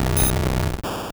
Cri de Spectrum dans Pokémon Or et Argent.